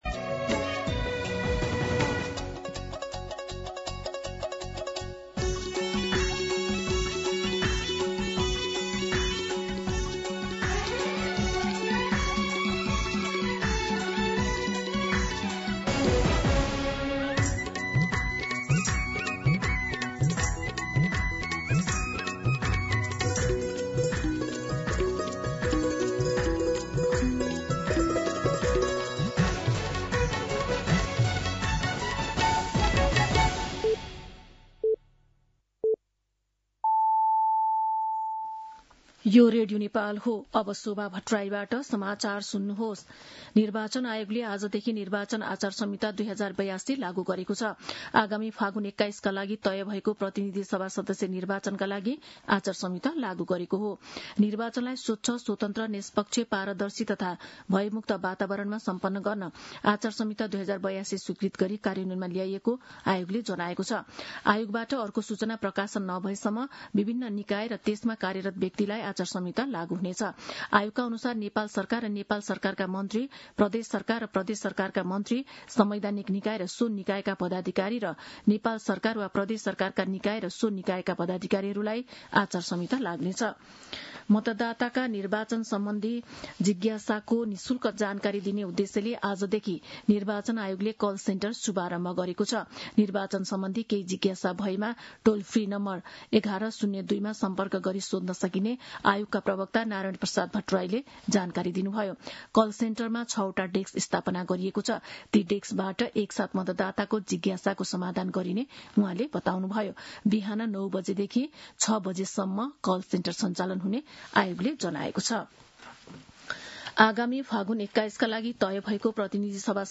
दिउँसो १ बजेको नेपाली समाचार : ५ माघ , २०८२